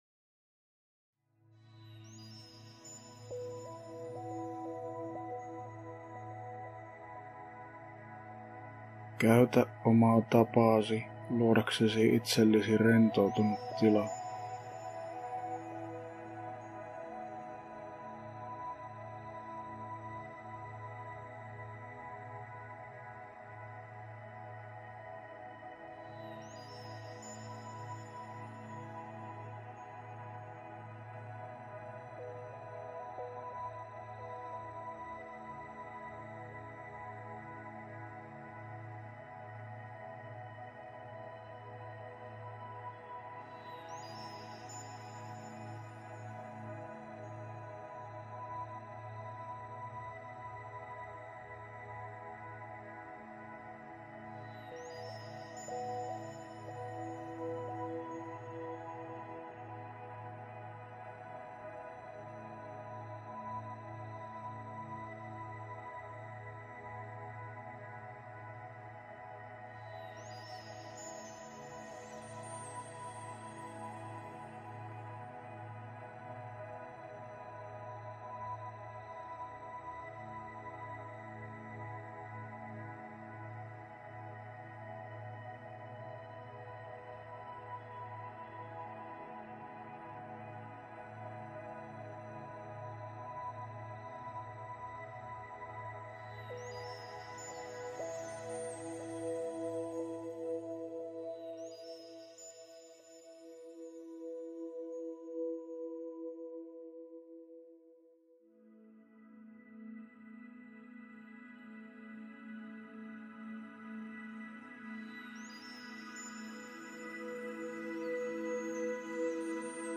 Panttivankien vapautusmeditaatio - Finnish guided audio
panttivankien_vapautusmeditaatio_finnish_guided_audio.mp3